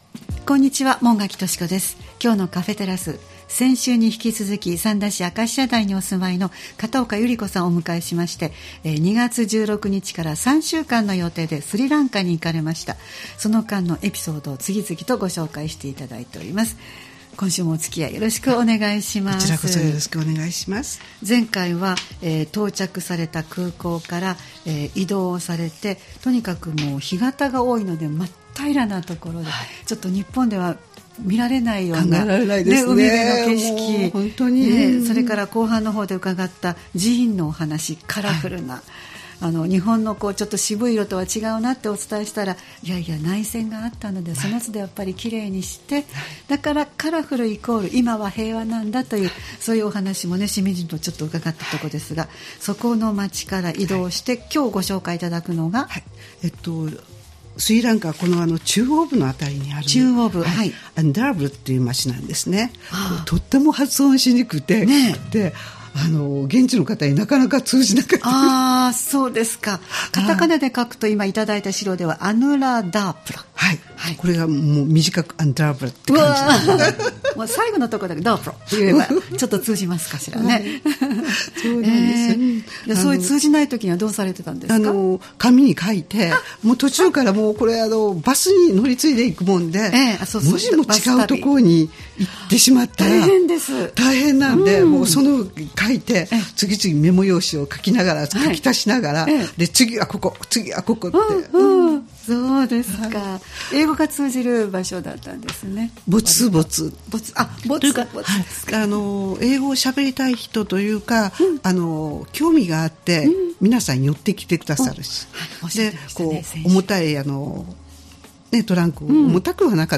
様々な方をスタジオにお迎えするトーク番組「カフェテラス」（再生ボタン▶を押すと放送が始まります）